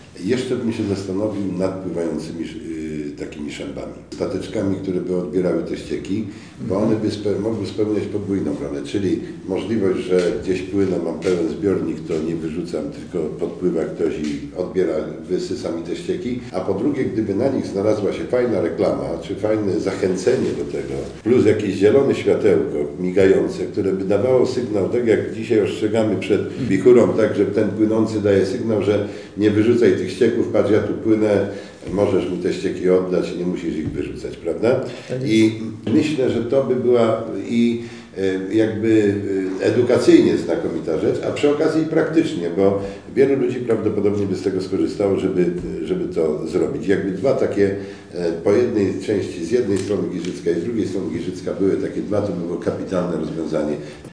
Swoim pomysłem o to, jak zadbać o czystość wód w jeziorach, podzielił się również Grzegorz Benedykciński, burmistrz Grodziska Mazowieckiego, a prywatnie miłośnik żeglarstwa.